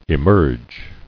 [e·merge]